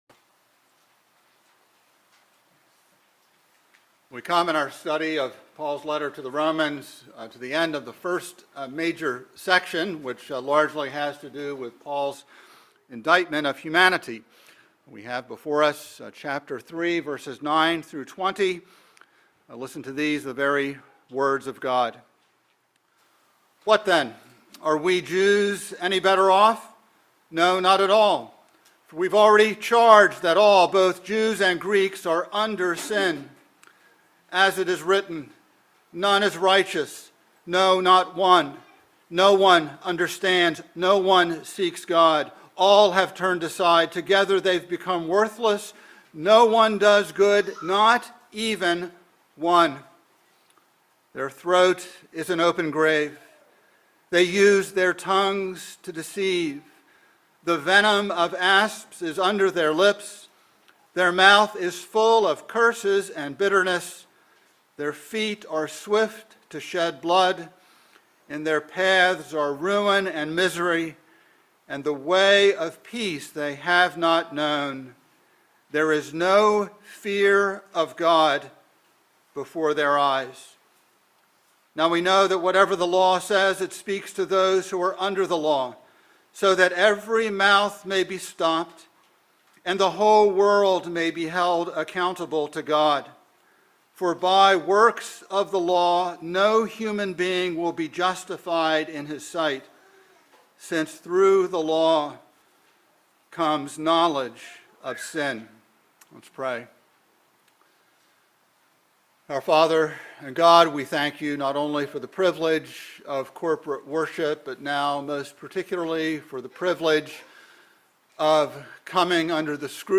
by Trinity Presbyterian Church | Jan 24, 2022 | Sermon